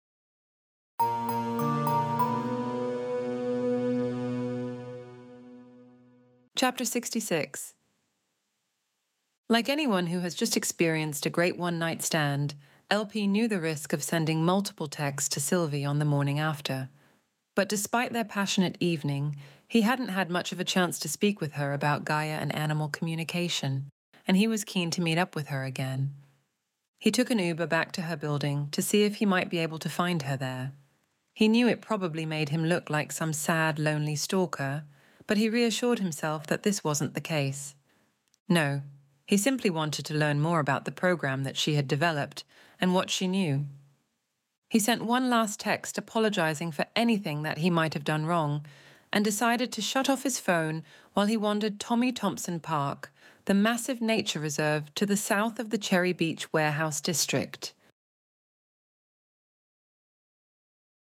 Extinction Event Audiobook